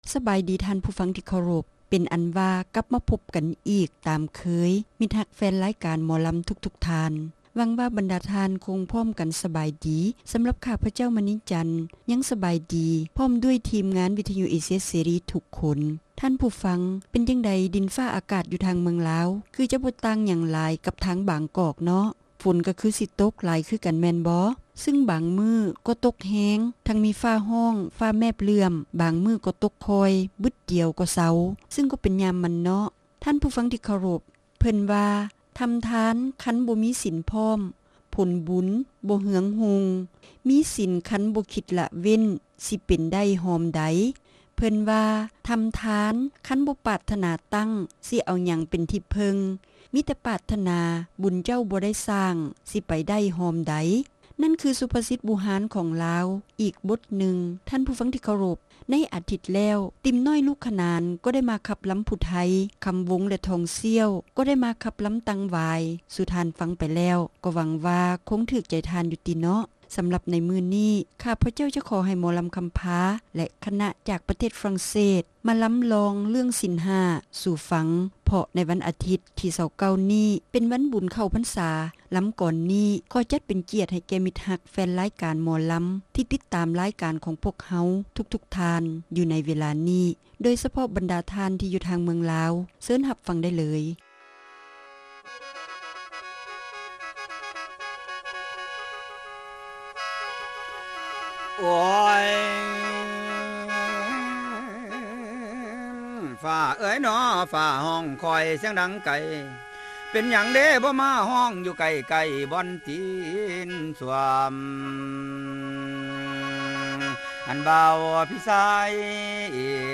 ຣາຍການໜໍລຳ ປະຈຳສັປະດາ ວັນທີ 27 ເດືອນ ກໍຣະກະດາ ປີ 2007